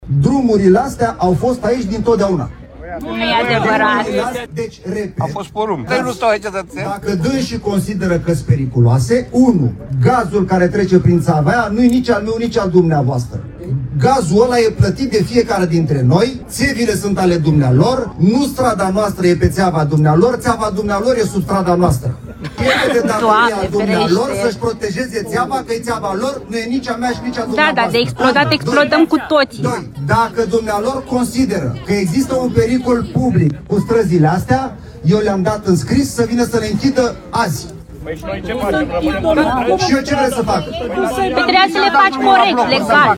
Oamenii au vociferat și au atras atenția asupra pericolului unei explozii, după cum reiese dintr-o filmare distribuită pe Facebook de Grupul de Inițiativă Civică Pallady.
„Doamne ferește!”, se aude o femeie exclamând.
„Păi și noi ce facem, rămânem fără străzi?”, a strigat un bărbat.